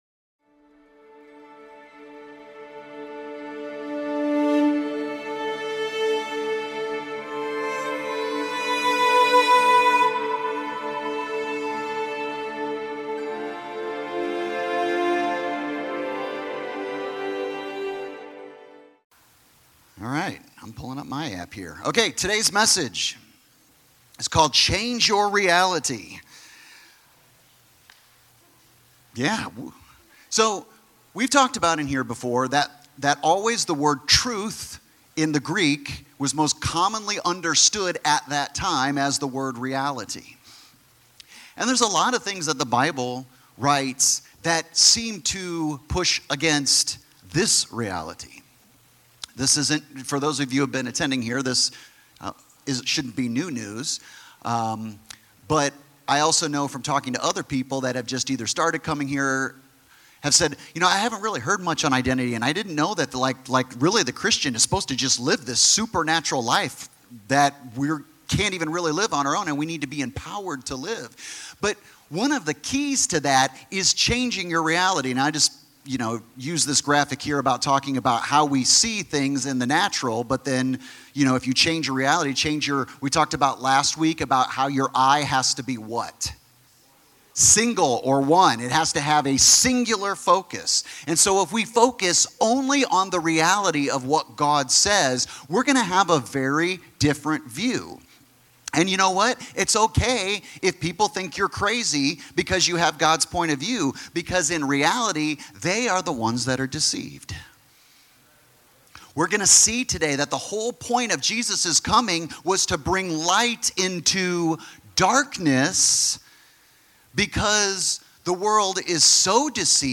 Sermons | 7 Mountains Church